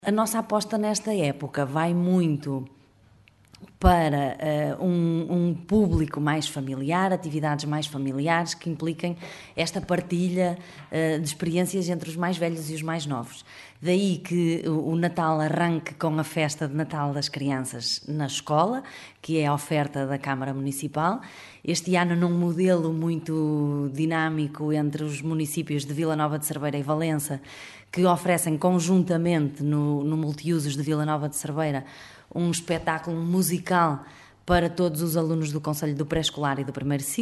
O pontapé de saída é dado com a festa de natal das escolas que este ano se realiza em conjunto com Valença, como revelou à Rádio Caminha Aurora Viães, vereadora da Cultura da Câmara de Cerveira.